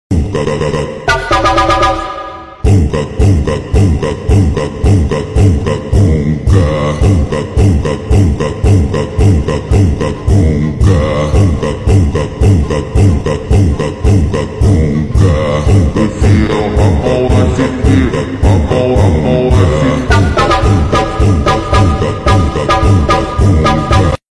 !!!!!!WARNING!!!!!!!!! VERY LOUD LOWER VOLUME